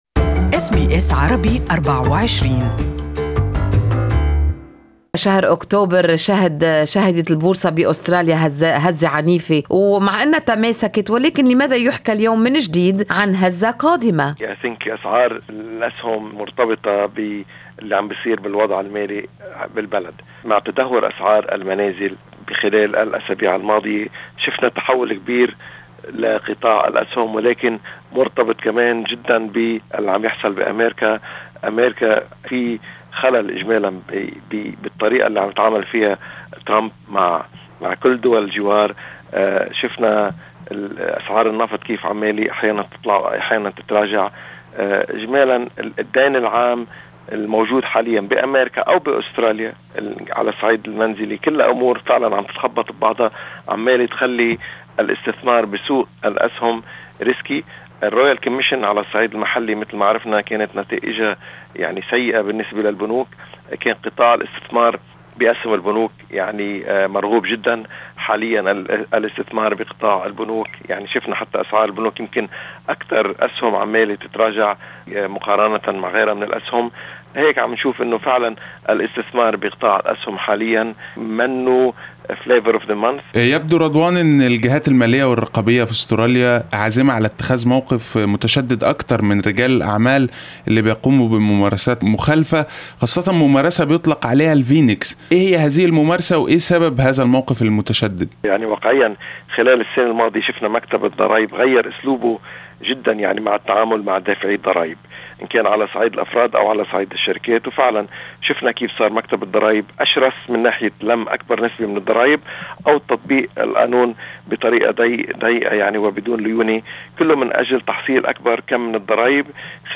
Full report in Arabic.